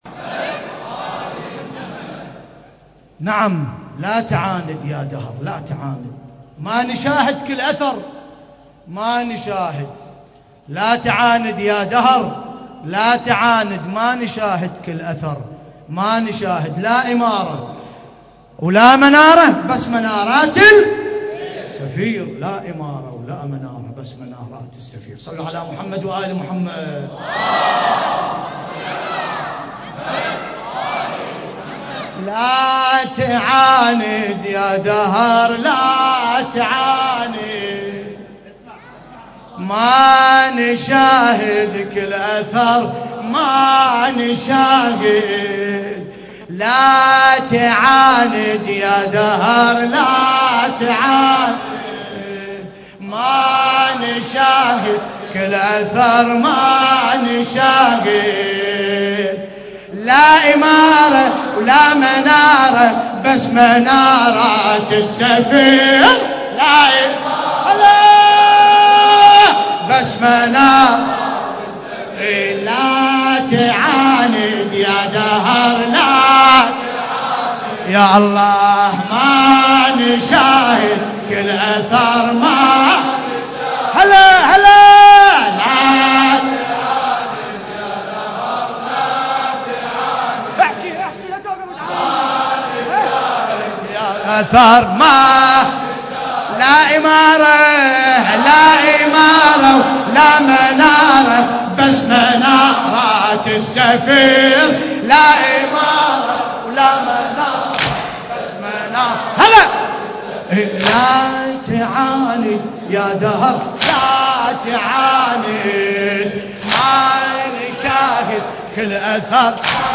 القارئ: باسم الكربلائي التاريخ: الليلة الخامسة من شهر محرم الحرام 1424 هـ - الكويت.